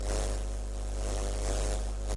舔食电缆" 噪声 curt 2
描述：受池田亮司的启发，我录下了我用手指触摸和舔舐连接到我的电脑线路输入口的电缆的声音。基本上是不同的fffffff，trrrrrr，和glllllll的最小噪音的声音...
Tag: 电缆 电气 电子 机械 噪声 信号